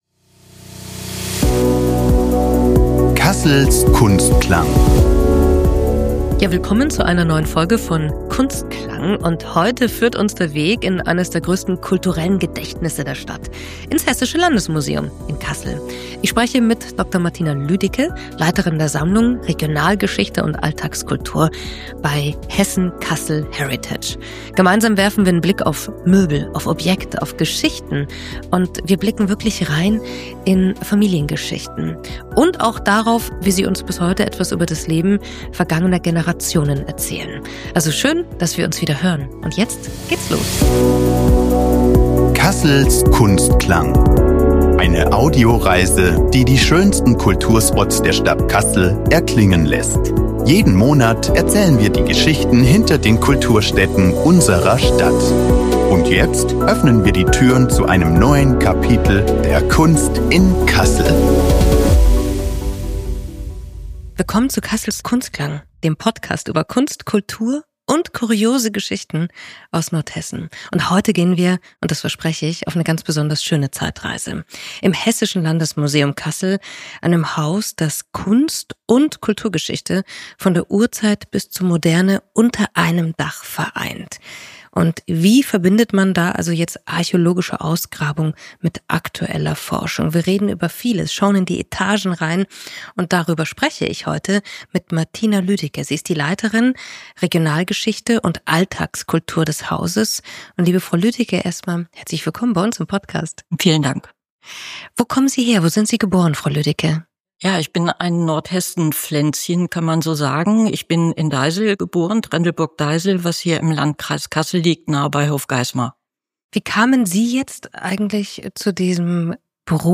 Ein Gespräch über Geschichte zum Anfassen – und darüber, wie Museen die Geschichten einer Region lebendig halten.